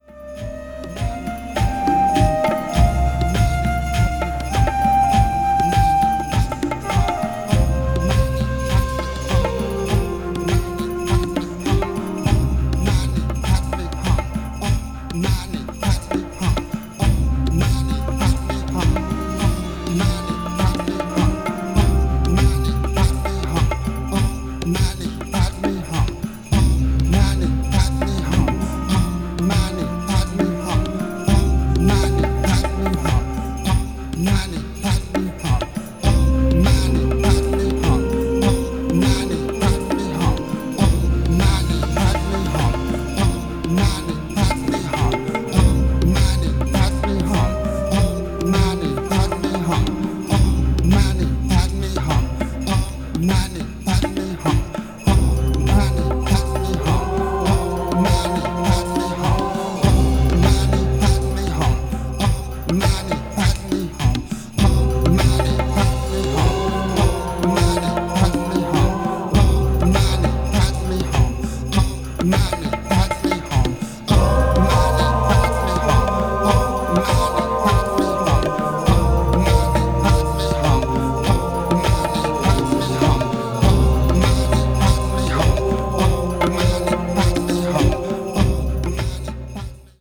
ズシッと腰にくるローなビートにアレンジされています。